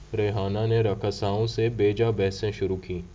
deepfake_detection_dataset_urdu / Bonafide /Speaker_11 /Part 1 /117.wav